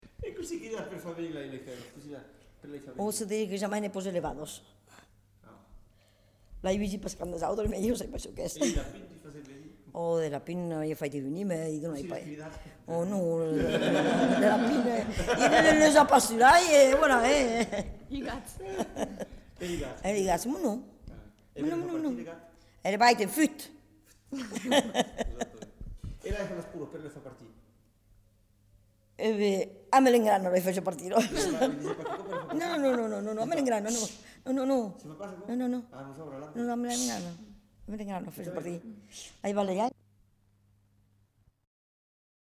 Appels au bétail
Aire culturelle : Lauragais
Lieu : Lanta
Genre : expression vocale
Type de voix : voix de femme
Production du son : crié
Classification : appel au bétail